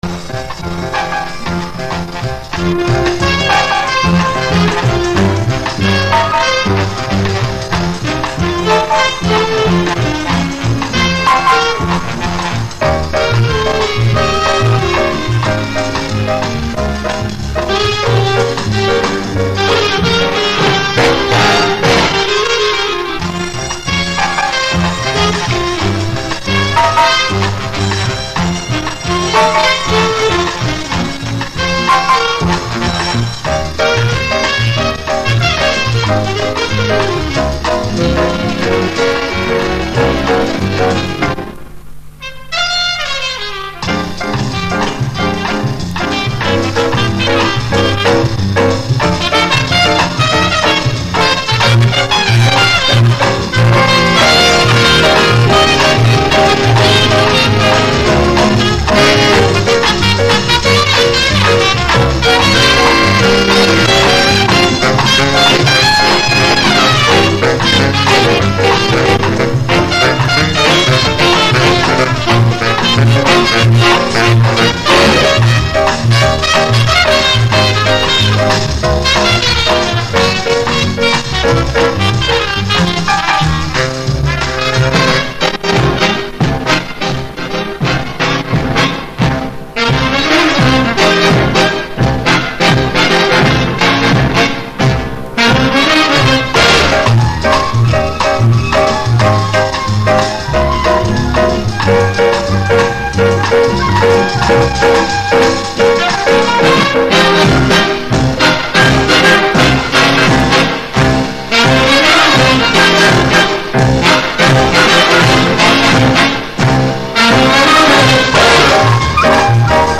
Подскажите название инструменталки